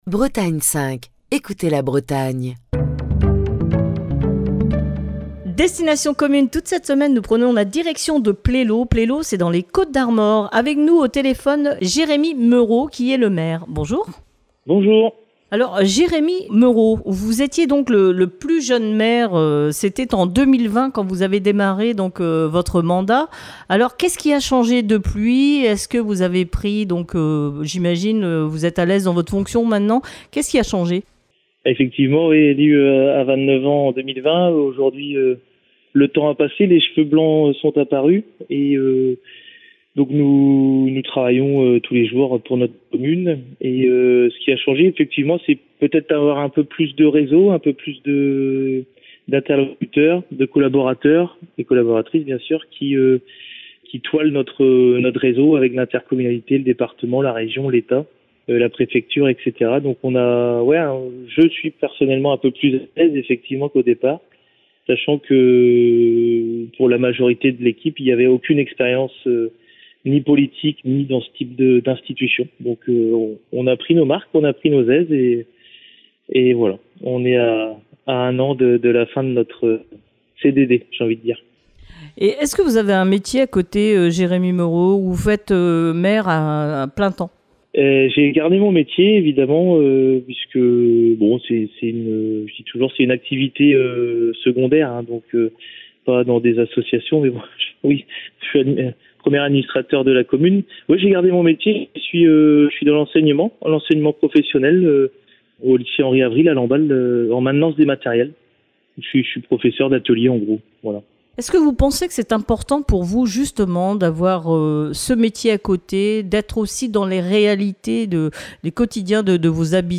Destination commune passe la semaine à Plélo, dans les Côtes d'Armor. Jérémy Meuro, le maire de Plélo, vous invite à découvrir sa commune